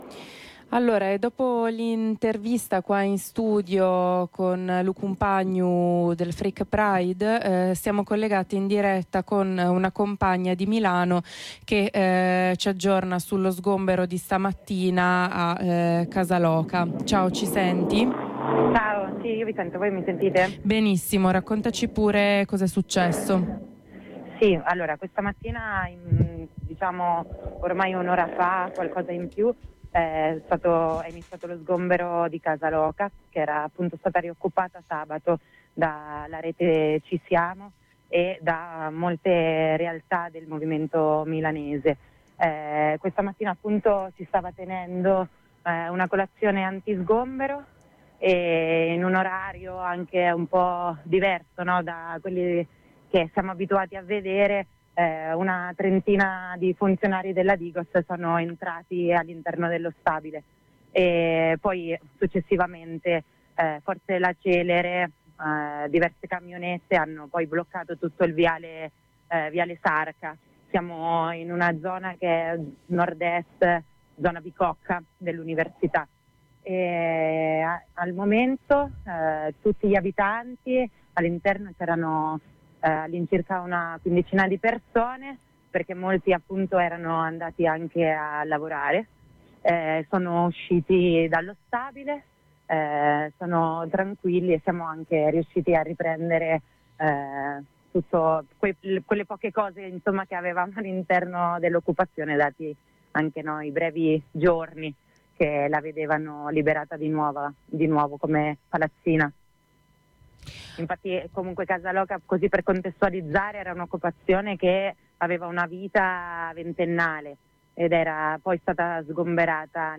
La lotta per il diritto all’abitare non si ferma. Ci facciamo aggiornare dalla compagna di Milano: